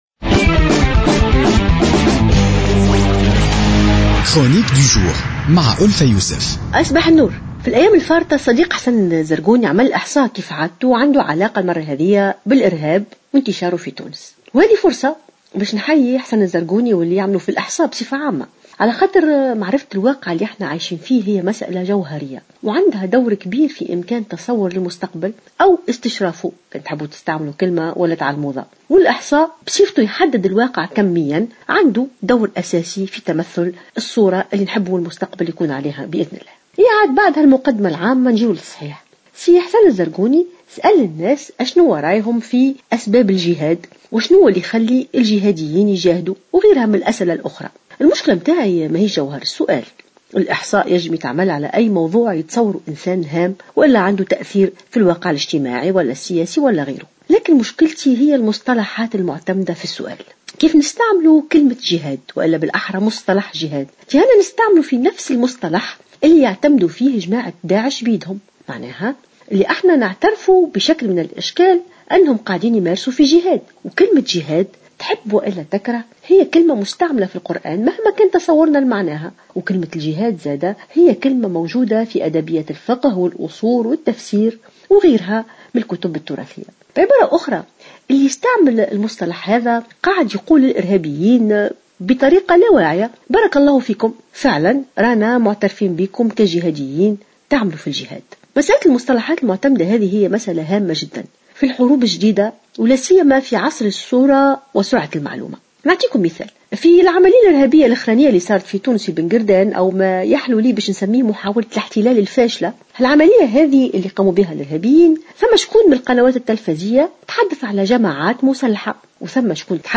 انتقدت المفكرة والجامعية ألف يوسف في افتتاحية اليوم الأربعاء استعمال مصطلح " الجهاد و الجهاديين" خلال الحديث عن العناصر الإرهابية.